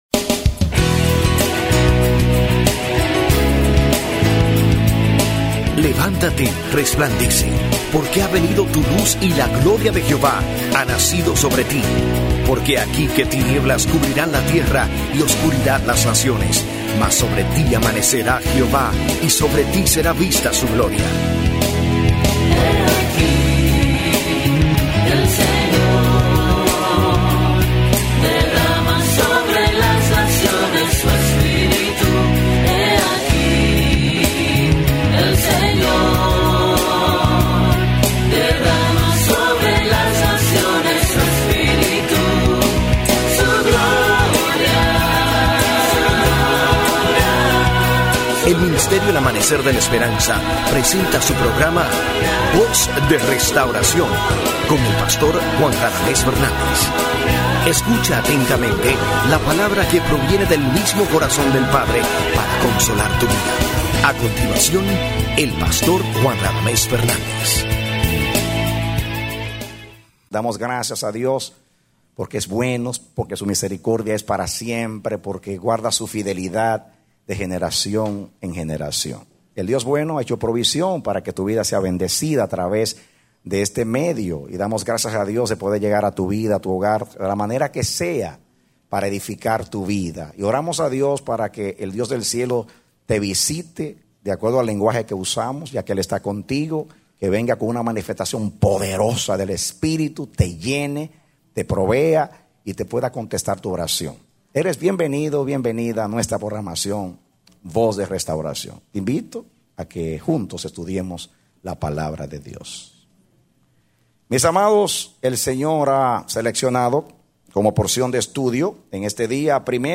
A mensaje from the serie "La Buena Profesión."